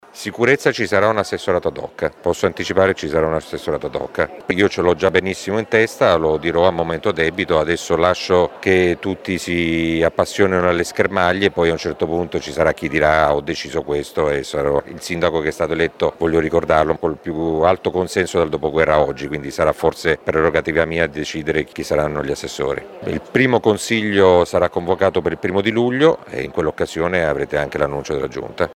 Sentiamo di seguito le parole di Massimo Mezzetti